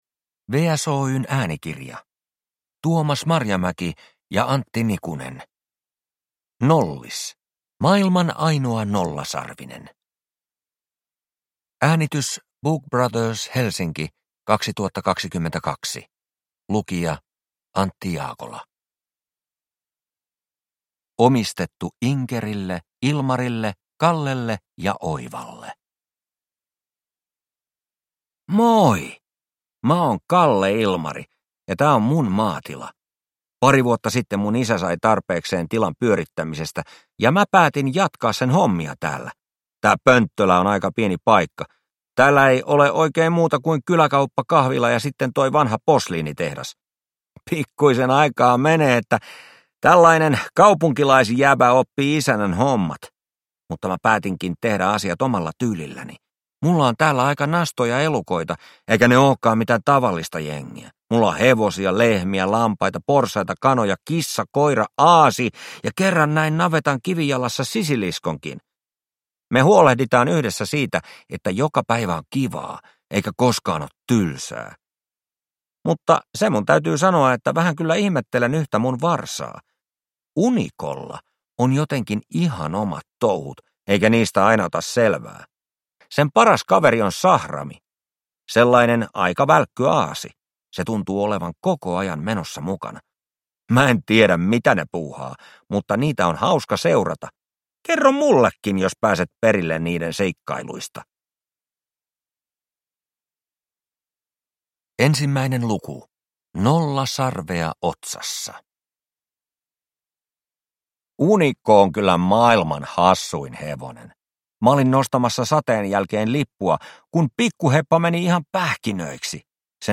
Nollis - Maailman ainoa nollasarvinen – Ljudbok